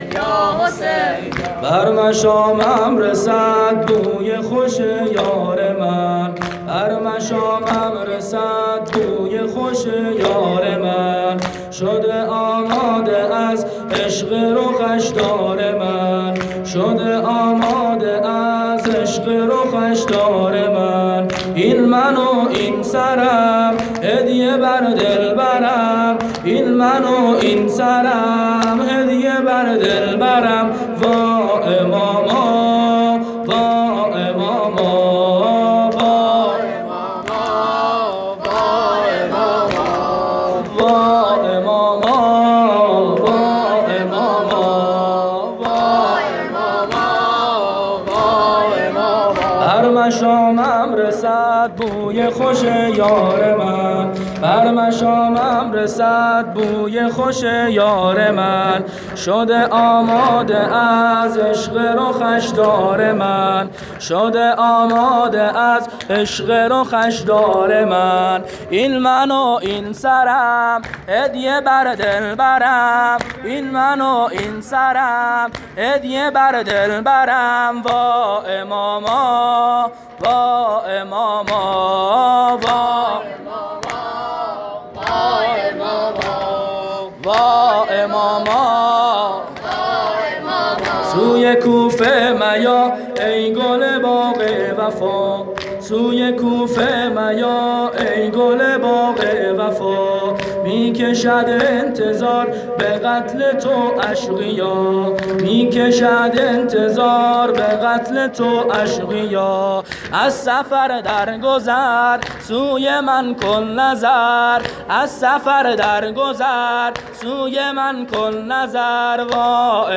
شب اول محرم98 هیئت میثاق الحسین (ع) سیستان